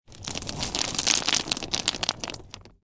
دانلود آهنگ کوه 4 از افکت صوتی طبیعت و محیط
جلوه های صوتی